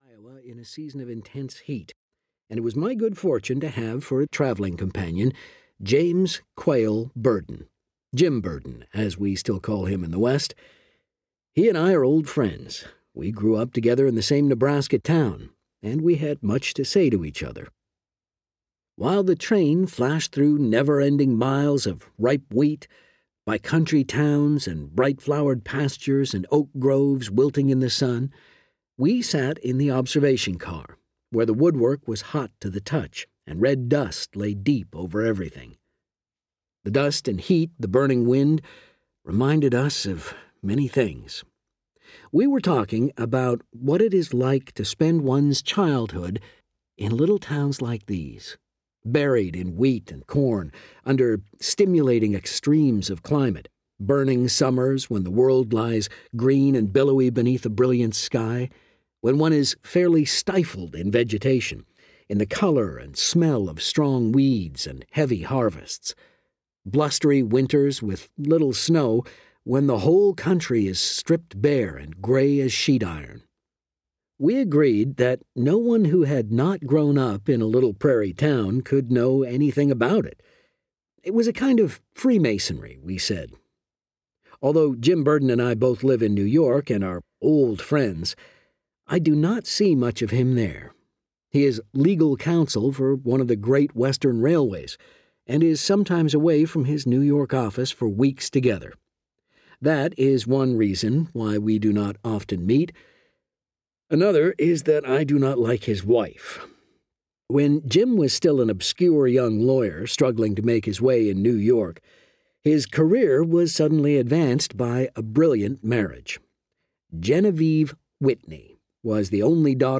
My Ántonia (EN) audiokniha
Ukázka z knihy